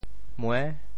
妹 部首拼音 部首 女 总笔划 8 部外笔划 5 普通话 mèi 潮州发音 潮州 muê6 文 潮阳 muê7 文 澄海 muê7 文 揭阳 muê7 文 饶平 muê7 文 汕头 muê7 文 中文解释 潮州 muê6 文 对应普通话: mèi ①称同父母比自己年龄小的女子：兄弟姊～ | 这是我的～～。